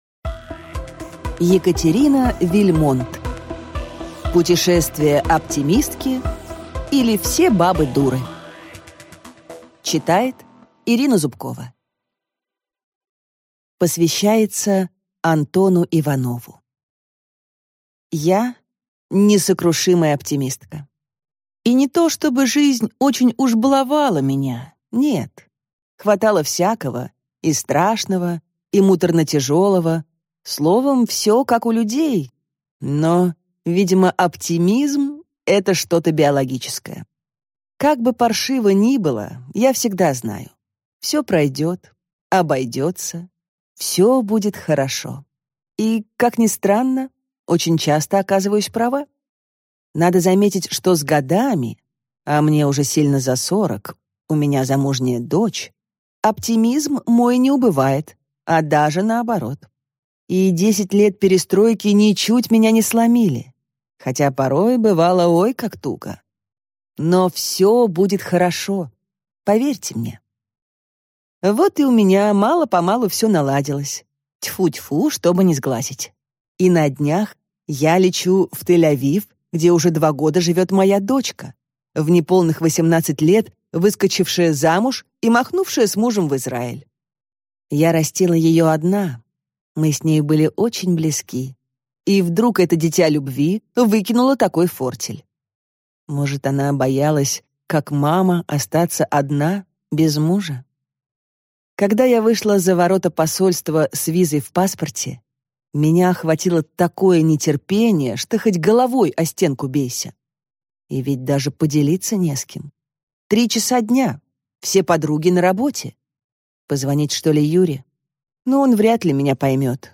Аудиокнига Путешествие оптимистки, или Все бабы дуры | Библиотека аудиокниг